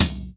TOM     4A.wav